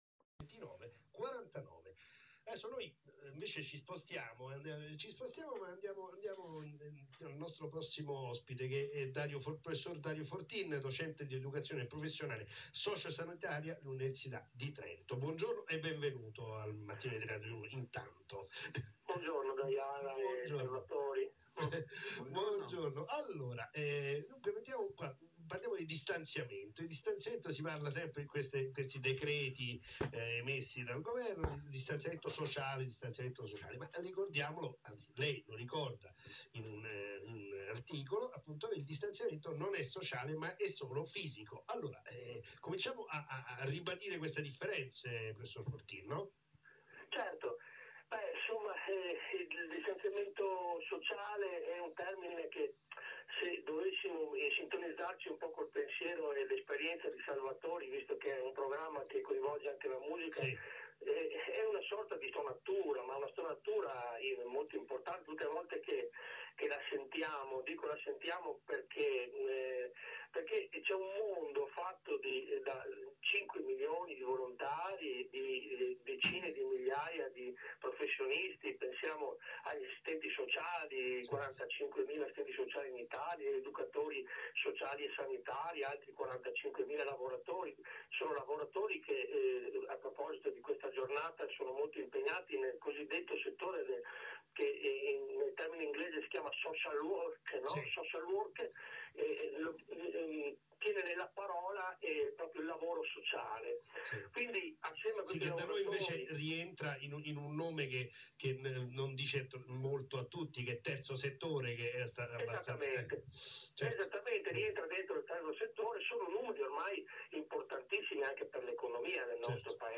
intervistato sul tema al Mattino di Radio1